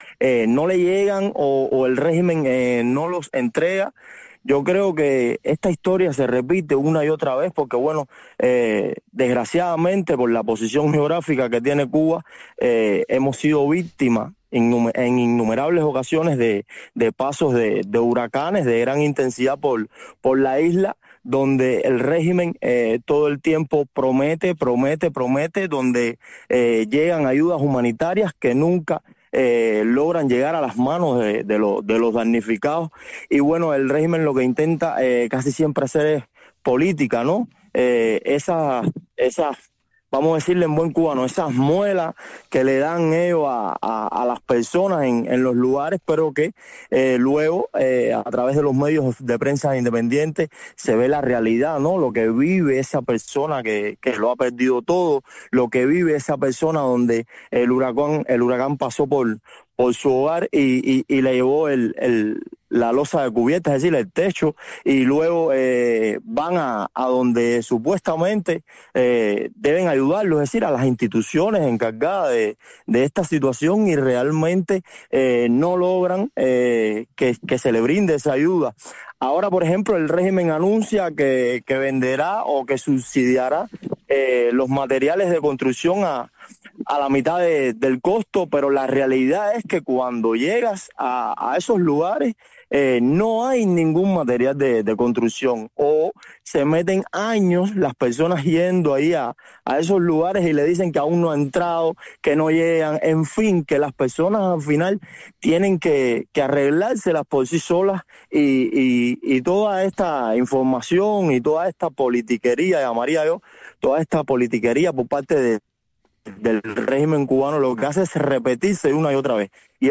conecta cada día con sus invitados en la isla en este espacio informativo en vivo, que marca el paso al acontecer cubano.